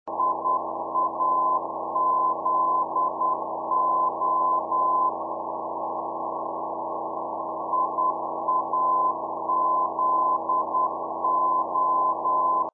I-AZO 110.9 Mhz, the Kalamazoo Localizer for ILS Rwy 35: (50 kb)